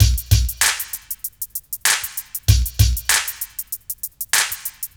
BD CLAP.wav